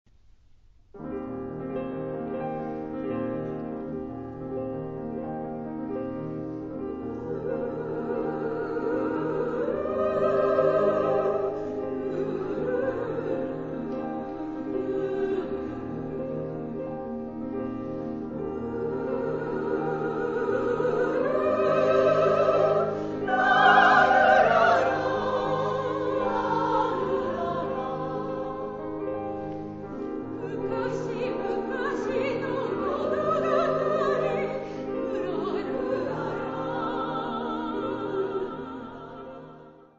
ライブ録音ＣＤ-R
中級用女声３部合唱曲集で，構成はソプラノ，メゾ･ソプラノ，アルトです。
歌いやすいメロディーとハーモニーで，親しみやすい女声合唱曲集に仕上がっています。